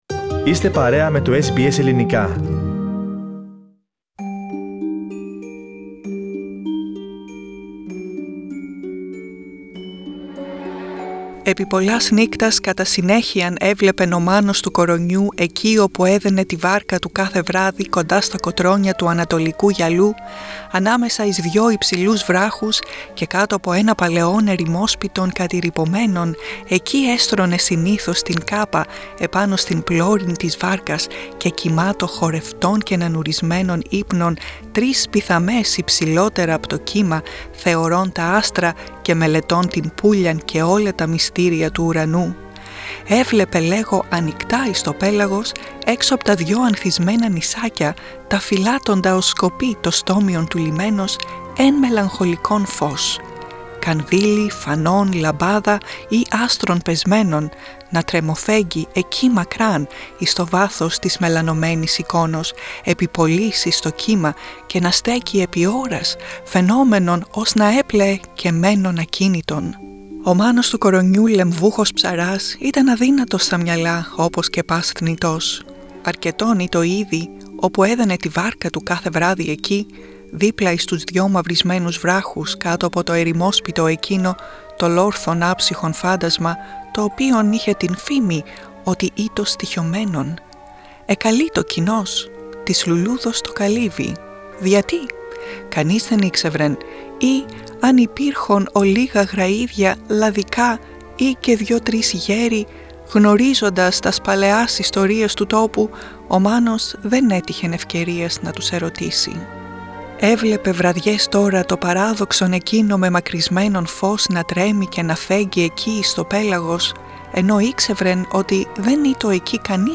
The flower in the Sea is a timeless tale of love and loss by Alexandros Papadiamantis.